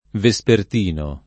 [ ve S pert & no ]